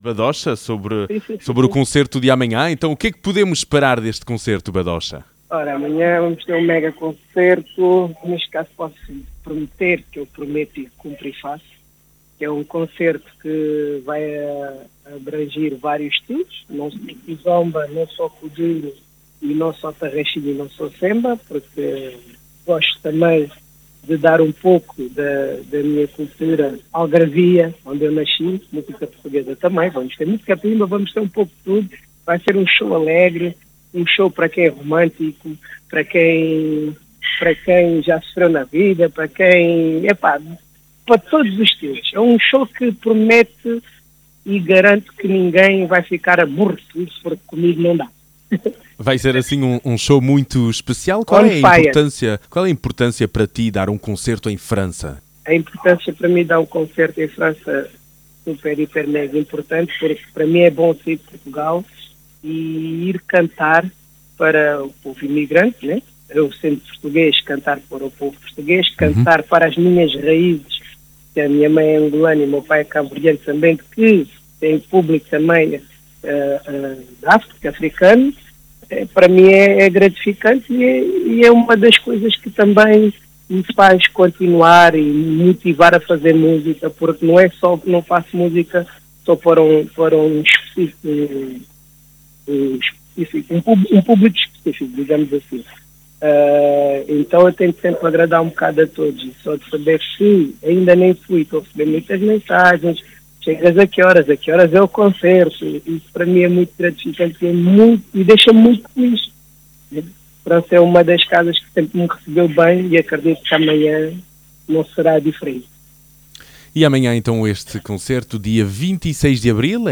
Entrevista exclusiva.